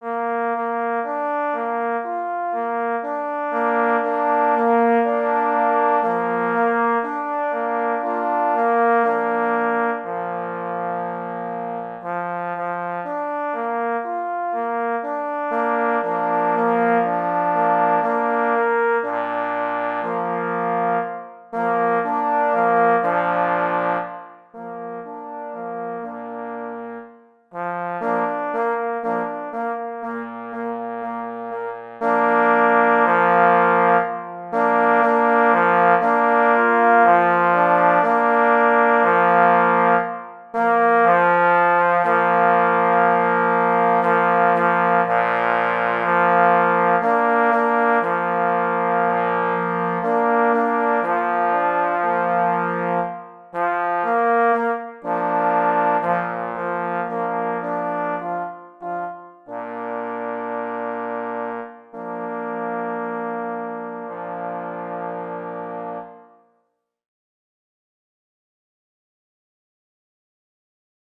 na 3 parforsy